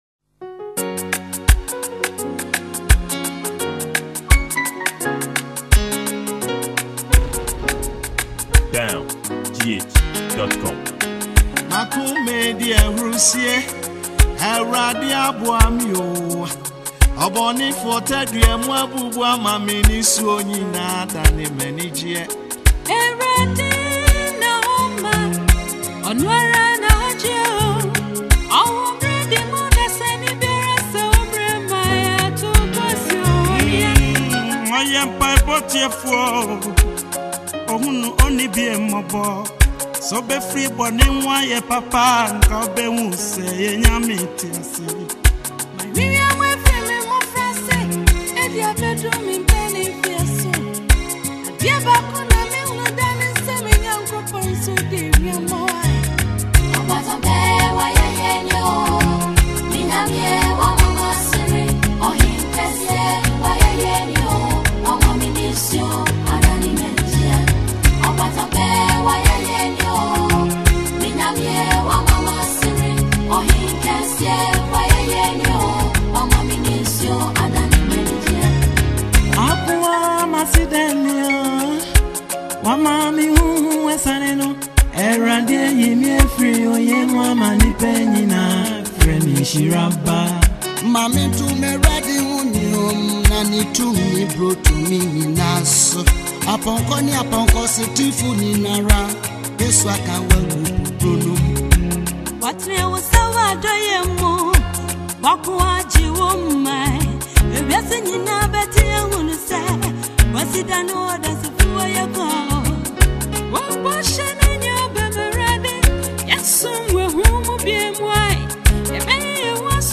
Ghana Music
Ghana Music, Gospel